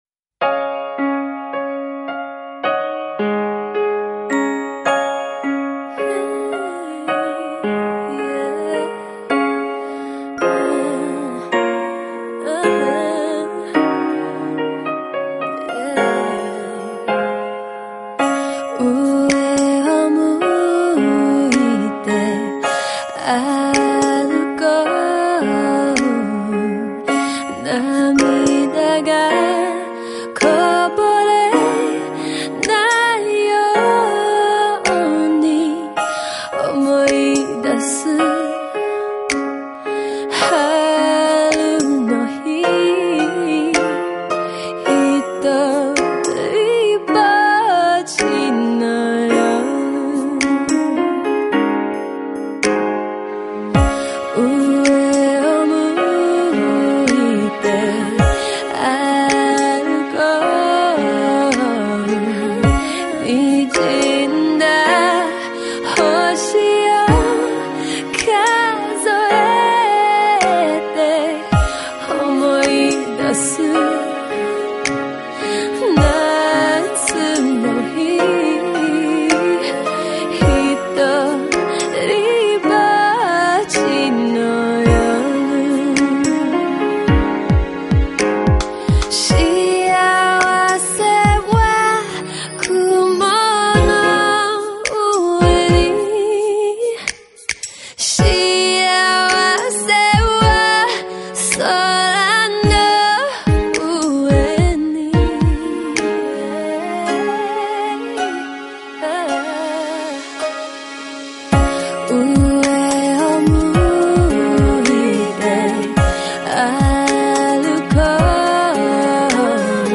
◎ 「新世代R&B歌姬」橫跨東西洋界限的首張翻唱之作
特別收錄以日文演唱